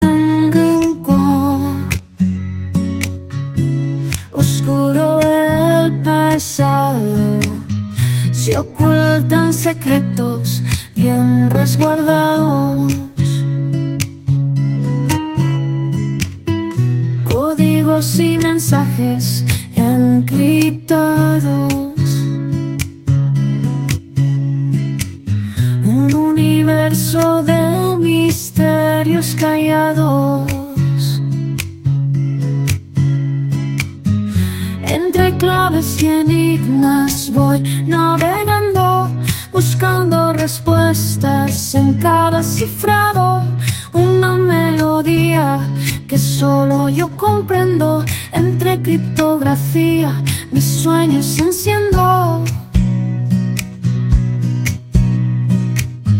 Canción generada con IA (Suno). Entre Claves y Enigmas (CC BY-SA)